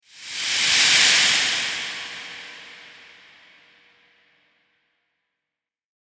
Nature Sounds / Sound Effects / Water Sounds 28 Dec, 2025 Spacey Sci-Fi Foam Rustling Transition Sound Effect Read more & Download...
Spacey-sci-fi-foam-rustling-transition-sound-effect.mp3